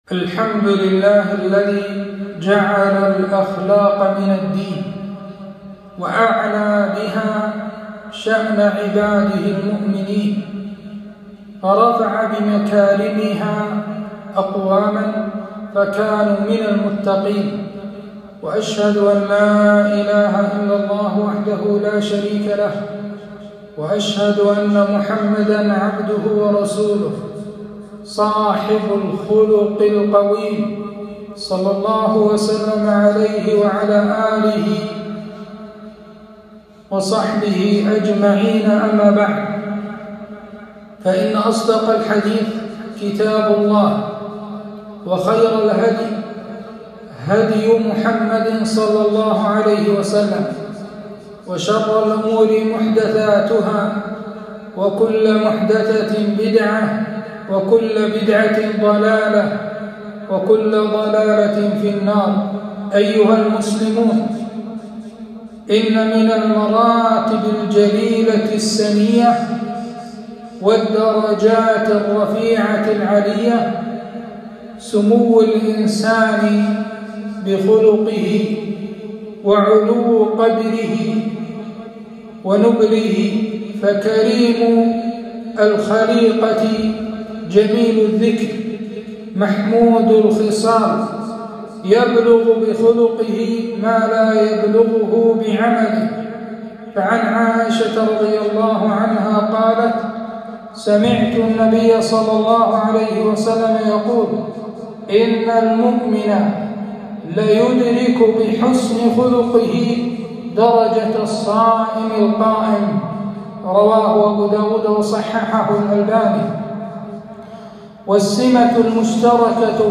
خطبة - من ستر مسلما ستره الله في الدنيا والآخرة